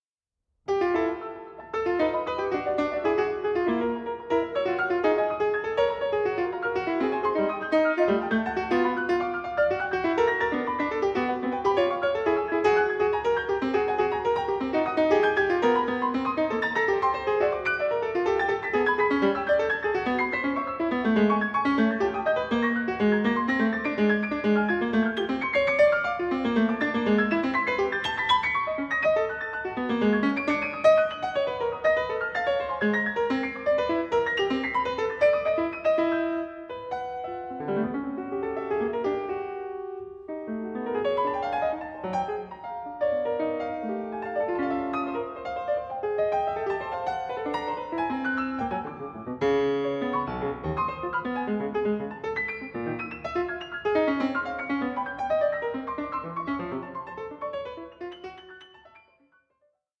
Frames (2016/2017) for piano four hands 10.11
NEW PIANO ETUDES BY A CONTEMPORARY MASTER